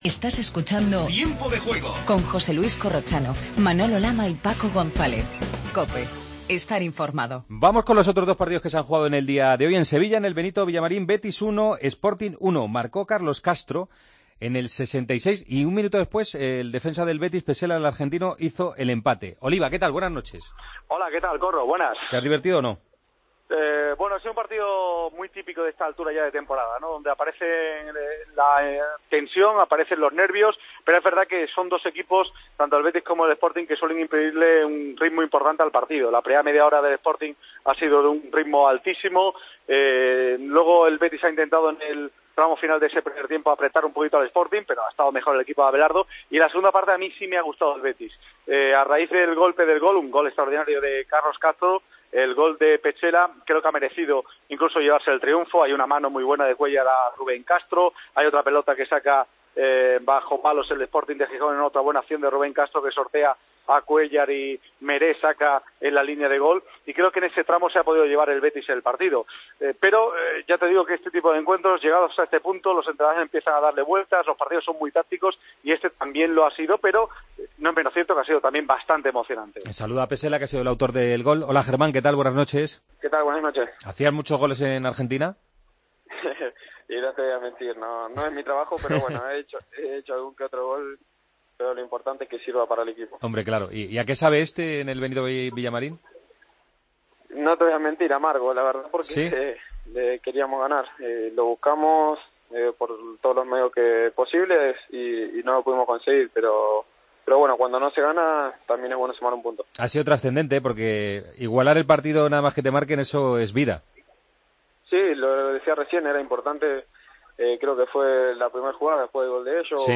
Previa de los encuentros de este domingo, con atención al derbi vasco entre el Athletic-Real Sociedad. Hablamos con Íñigo Martínez.